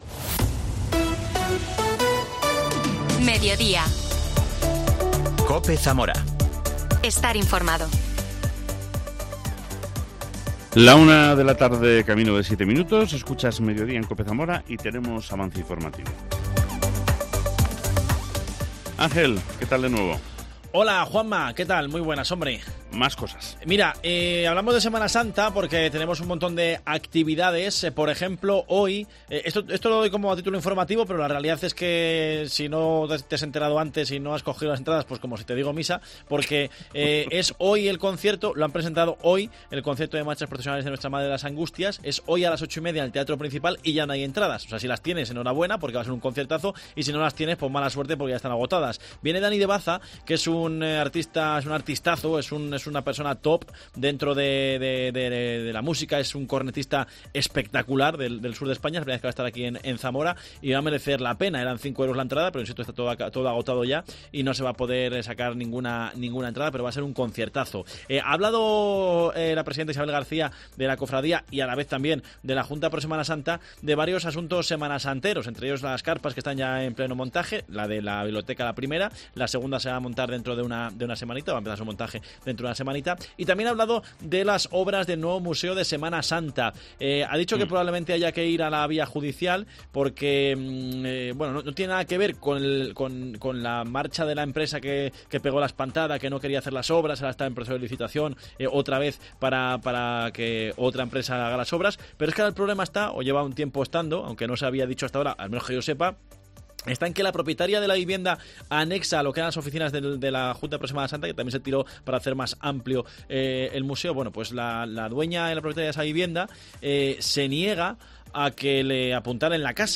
AUDIO: La consejera de Familia e Igualdad de Oportunidades, Isabel Blanco, desgrana las actuaciones en igualdad desarrolladas durante el último año.